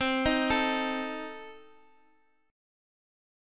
Cm6 Chord